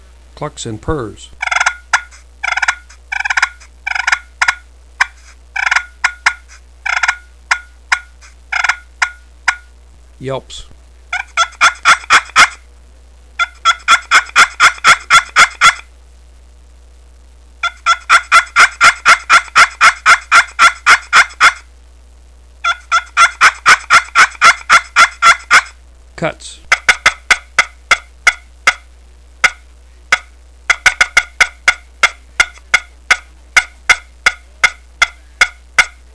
Listen to 36 seconds of clucks & purrs, yelps, and cutts